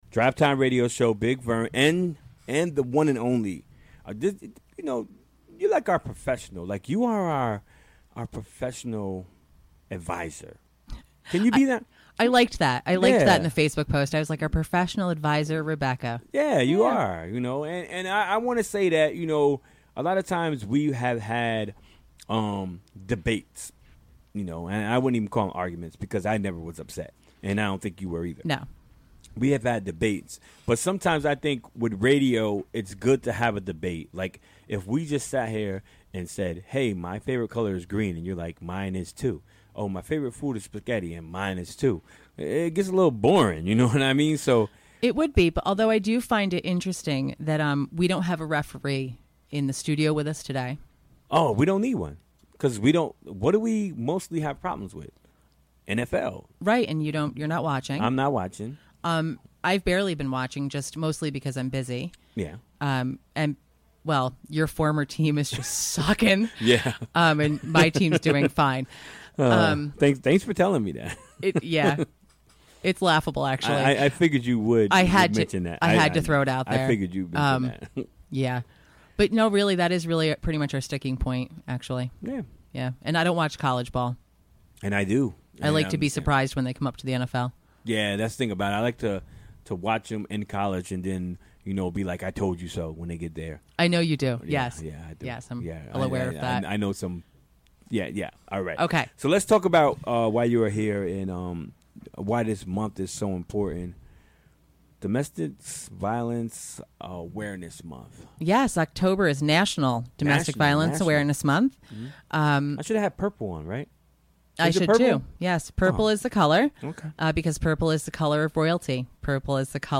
Recorded during the WGXC Afternoon Show Wednesday, October 11, 2017.